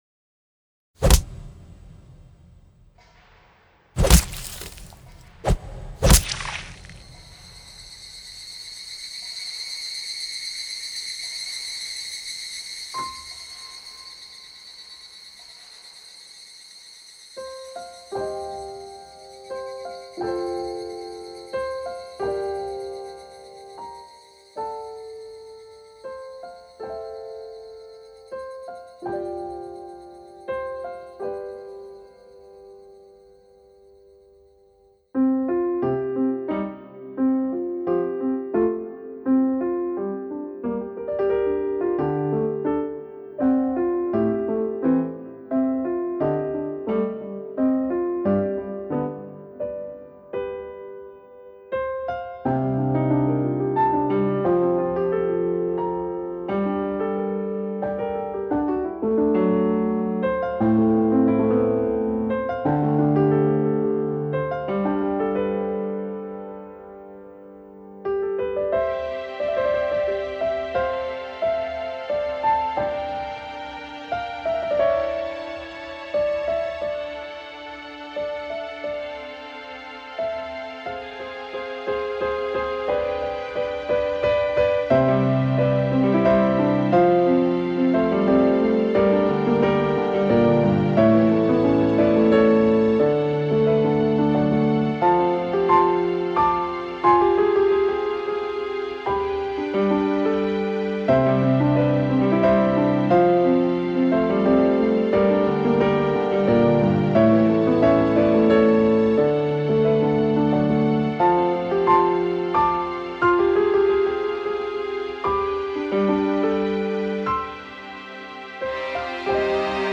トラック1/2/8/9、全てメロディの冒頭は、調は違えど構造は同じになっています。